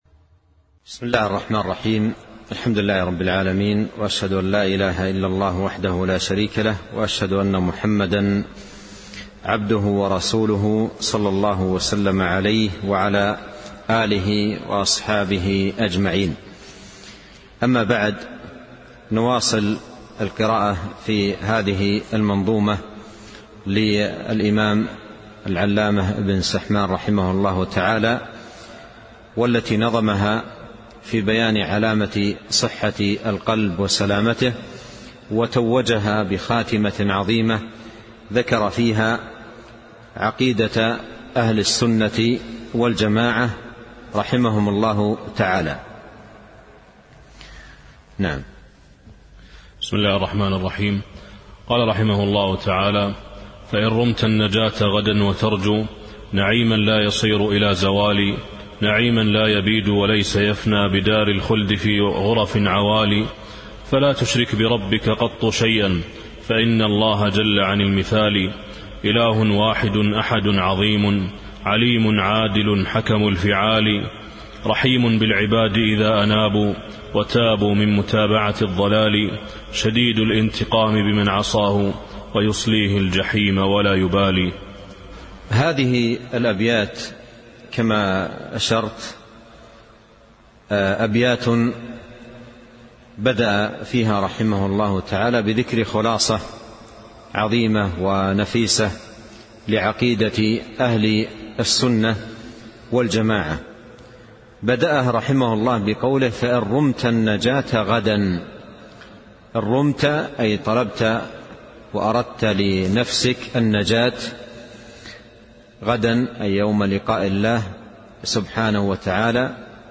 شرح منظومة في علامات صحة القلب الدرس 3